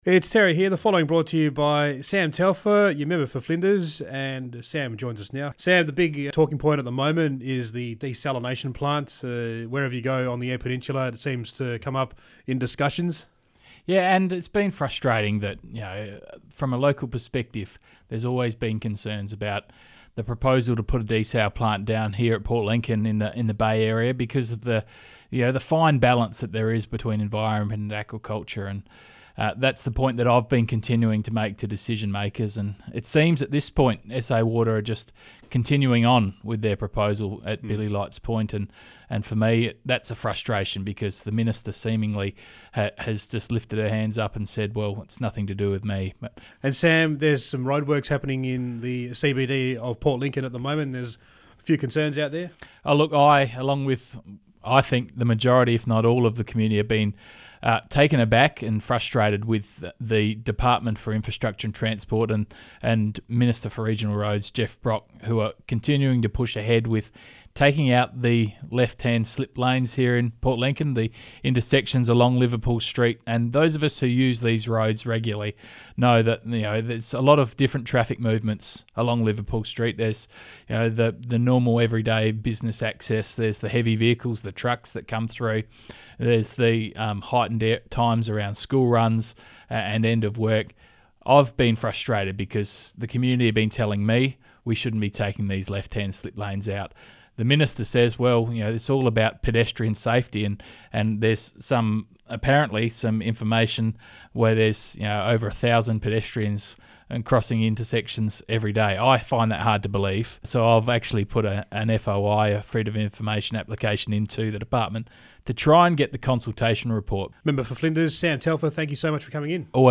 5CC_MAG899-MARCH-INTERVIEW.mp3